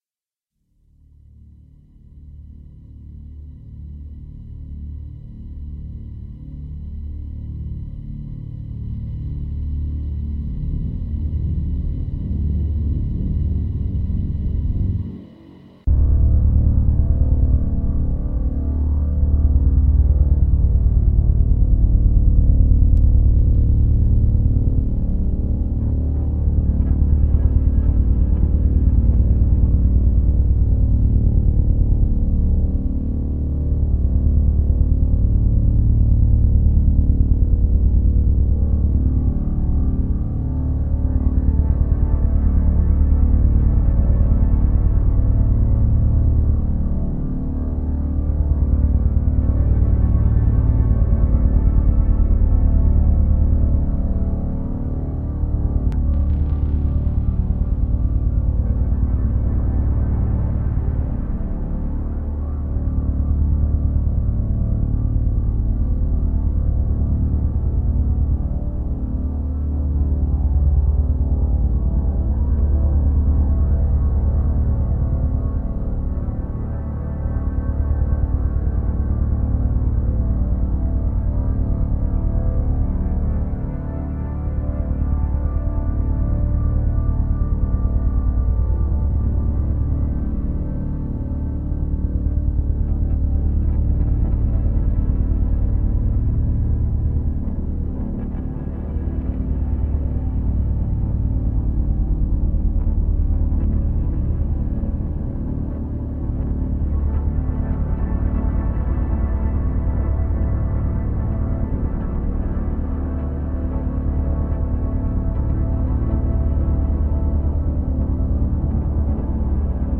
SynthBoard electronica - no title #1
Habe mich entschlossen, und denke das passt auch recht gut, noch eine Effekt-Gitarre/Delay-Gitarre/DarkFuzz-Gitarre mit einzuspielen.